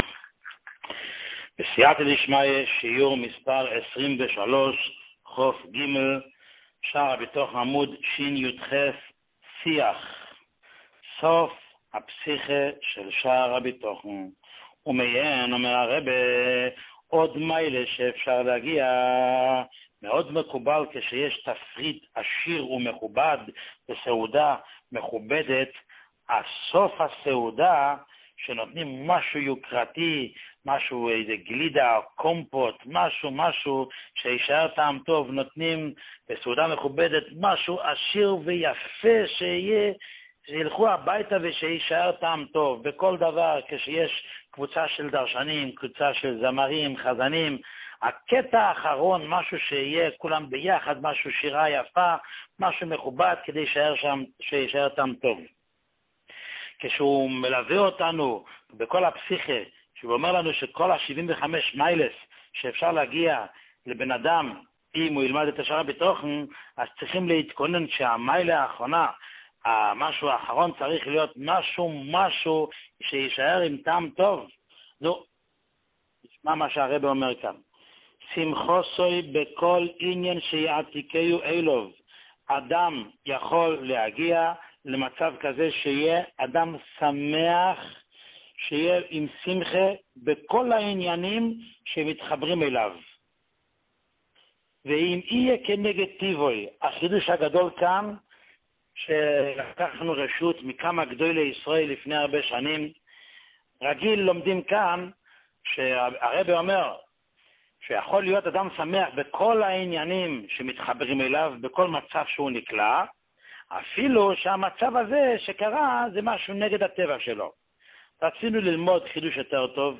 שיעורים מיוחדים
שיעור 23